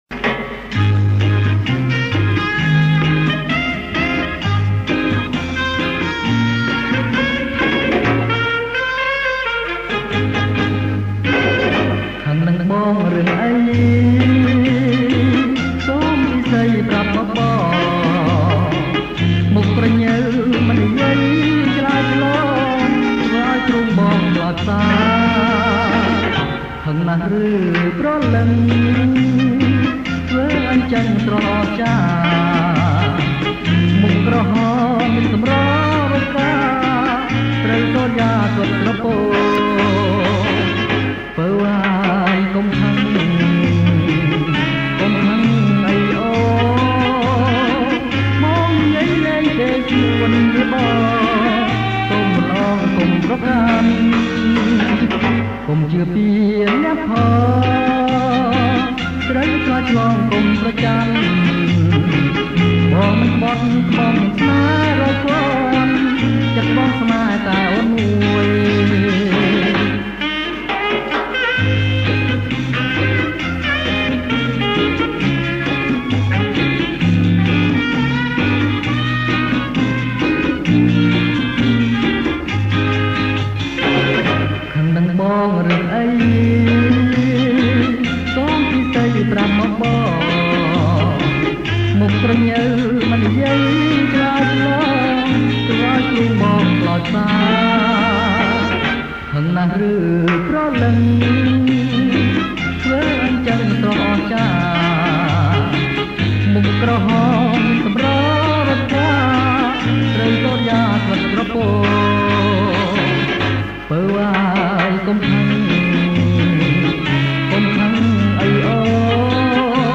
• ប្រគំជាចង្វាក់ Cha Cha Cha